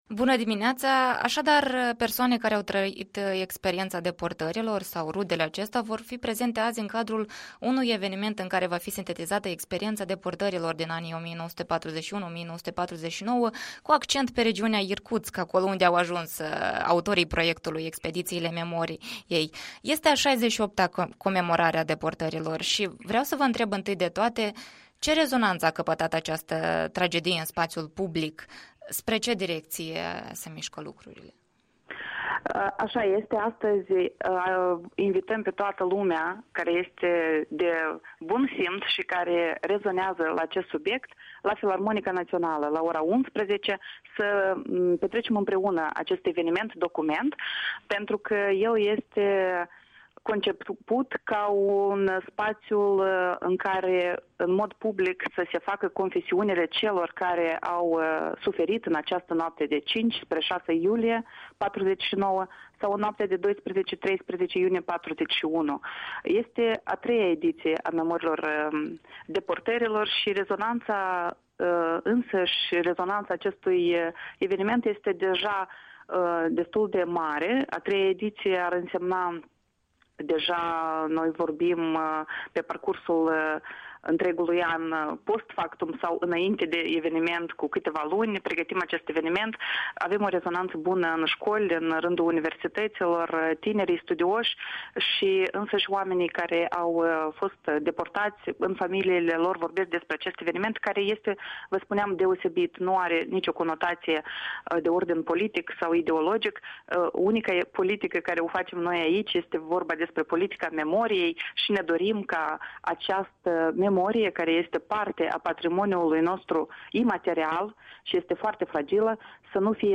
Interviul dimineții la EL